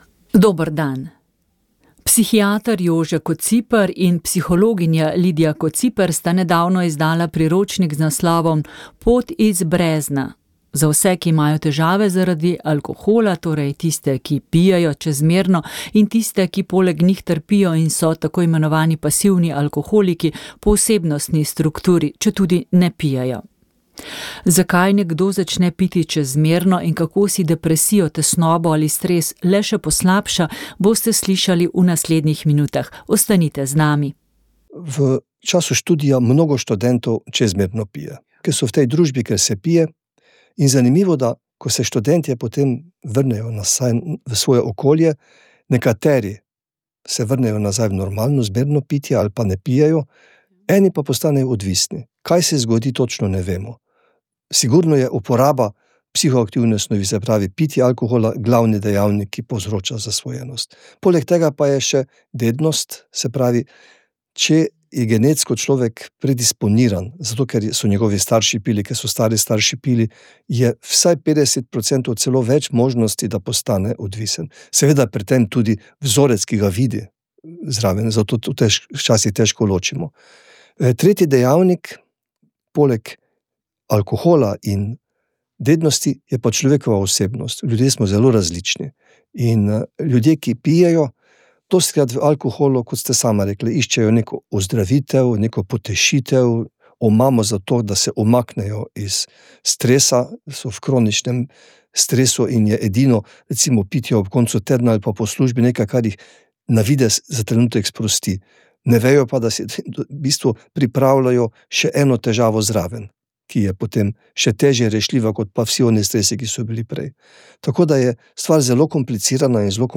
Naša gostja je bila politologinja in raziskovalka